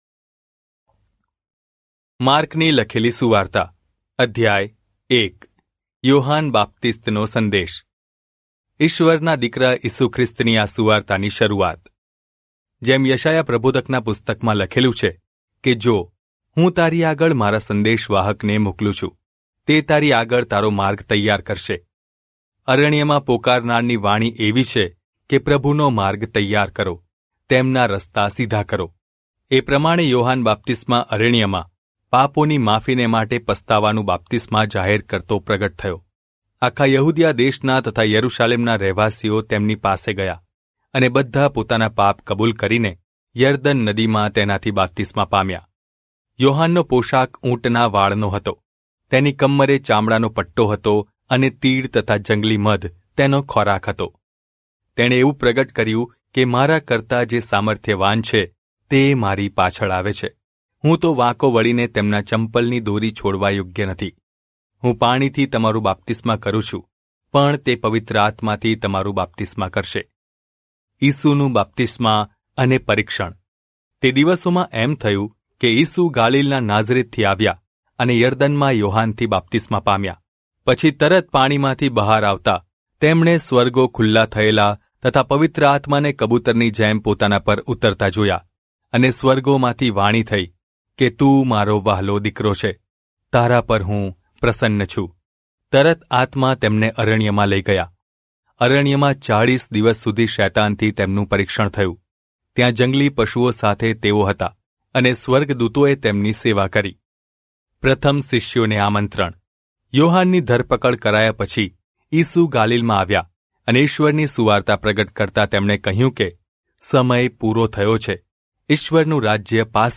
gujarati-bible-09718-genesis-1.mp3
• Word for word narration
• Voice only Bible reading